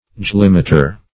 joulemeter - definition of joulemeter - synonyms, pronunciation, spelling from Free Dictionary
Joulemeter \Joule"me`ter\ (j[=oo]l"m[=e]`t[~e]r; joul"m[=e]`t[~e]r), n.
joulemeter.mp3